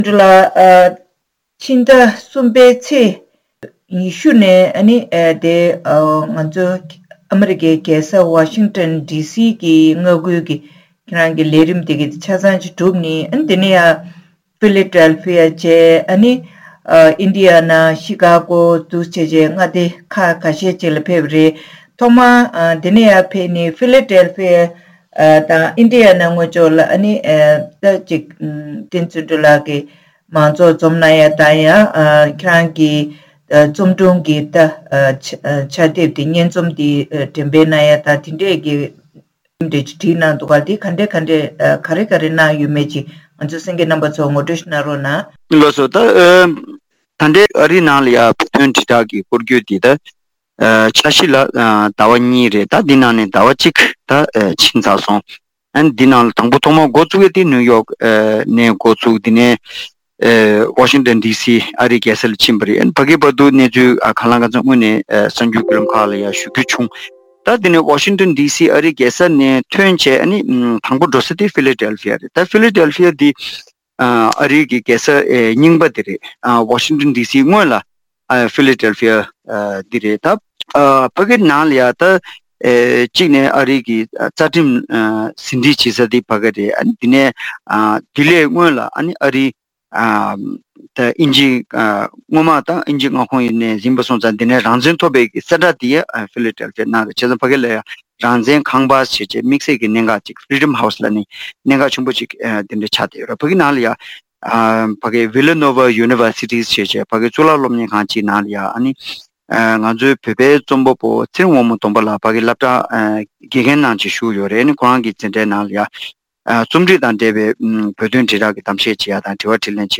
བཅར་འདྲི་ཞུས་པ་ཞིག་གསན་རོགས་ཞུ།།